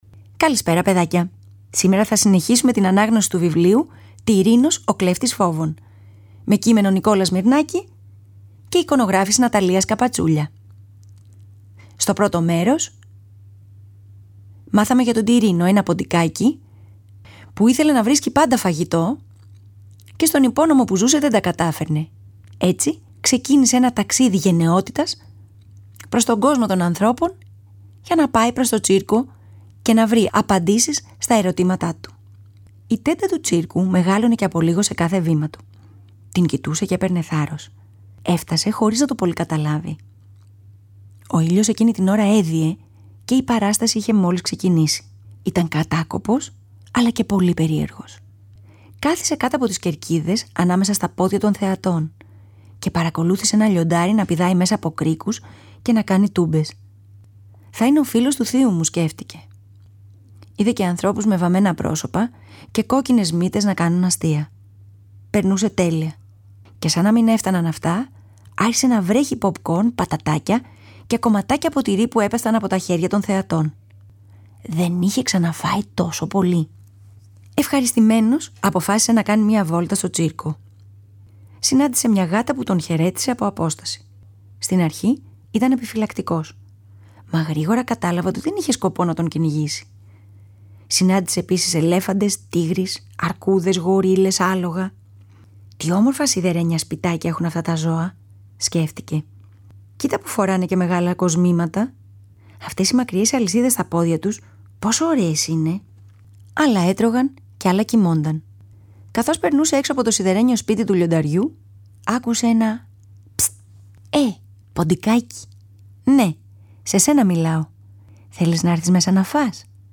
Θα το διαβάσουμε σε δύο μέρη.